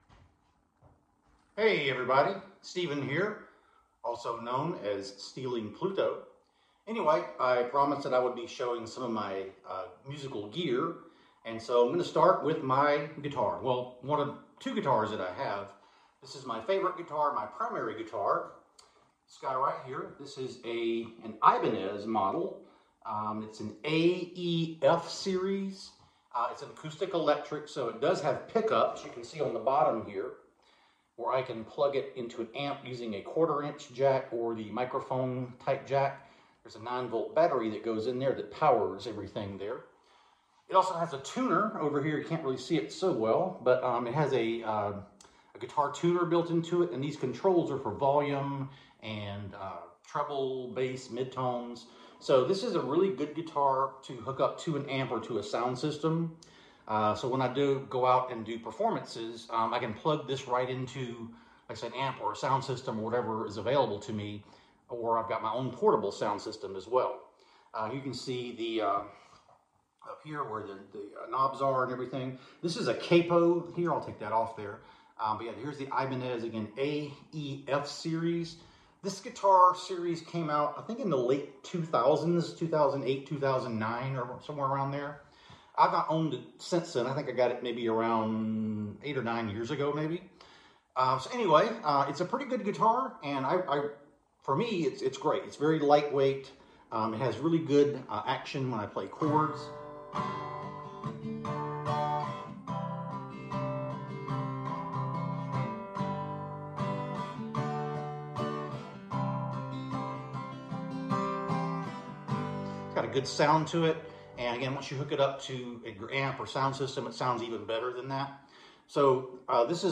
Ibanez AEF acoustic electric guitar.